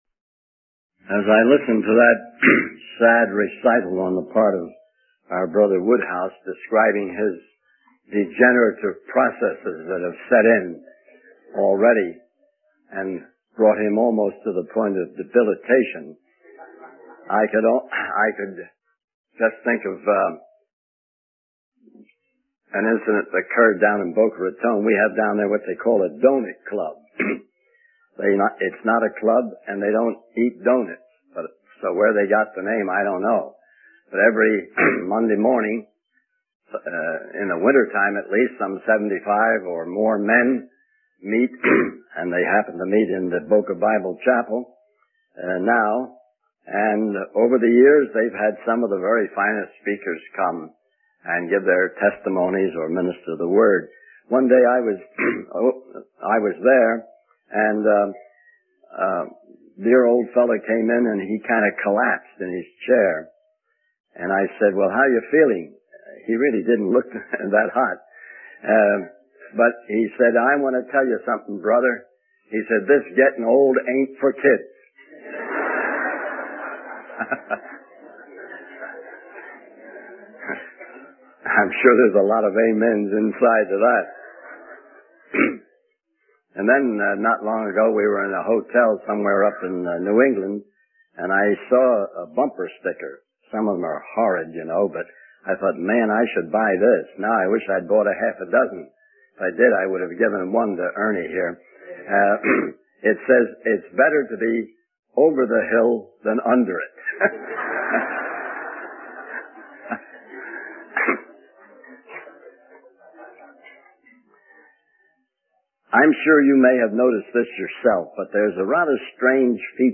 In this sermon, the speaker reflects on the marvel of how God created each person uniquely, with different physical features. He emphasizes that although we may think we look similar, we are actually different and have different needs for Christ.